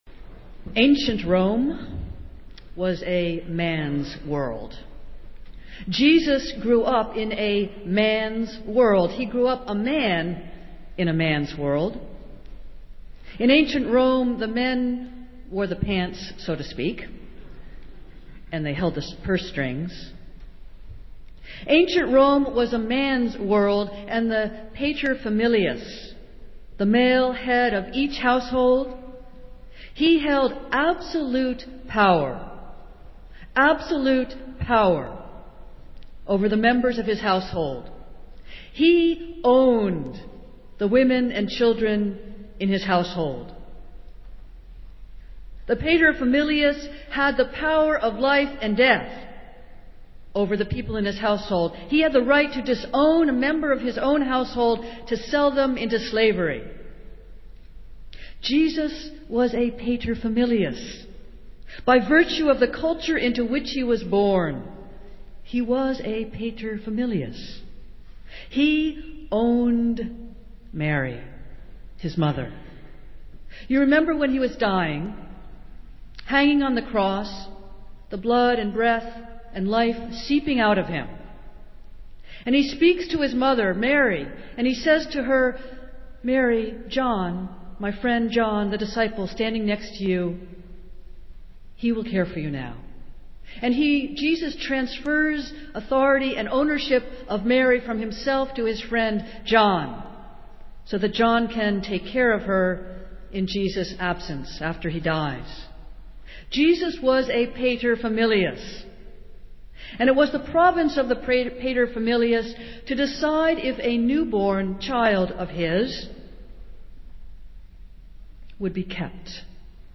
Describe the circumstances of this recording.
Festival Worship - Third Sunday in Lent